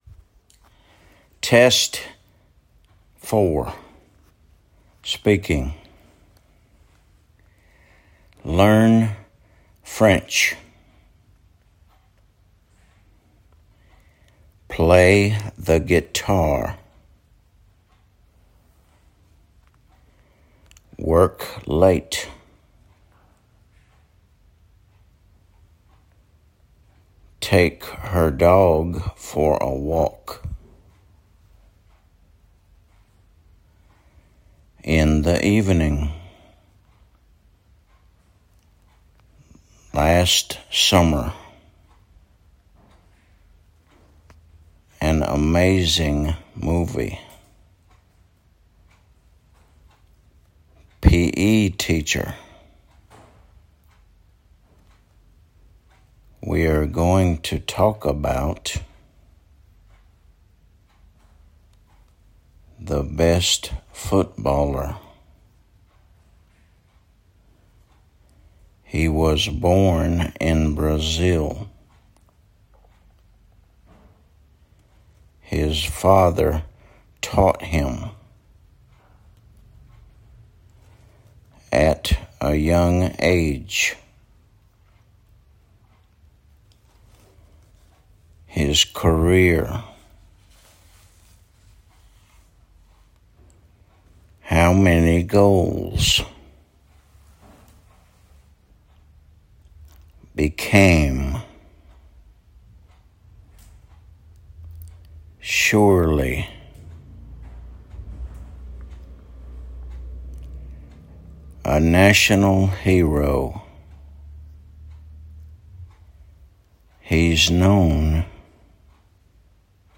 Test 624: Speaking
learn French /lɜːn frɛnʧ/
play the guitar /pleɪ ðə ɡɪˈtɑː/
the best footballer /ðə bɛst ˈfʊtbɔːlə/
a national hero /ə ˈnæʃənl ˈhɪərəʊ/